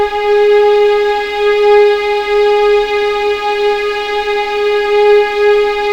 G#4LEGPVLN R.wav